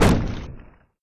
box_explosion.mp3